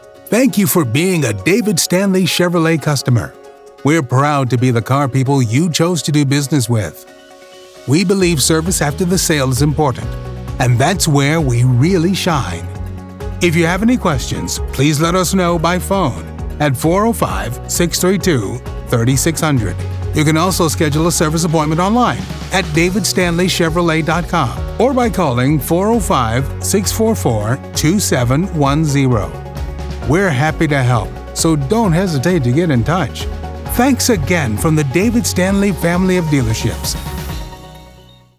Male
American English
Natural, Smooth, Warm, Assured, Authoritative, Bright, Confident, Cool, Corporate, Deep, Engaging, Friendly, Gravitas, Versatile
Microphone: Neumann TLM103, Sennheiser MKH416